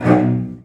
CELLOS.C#2 S.wav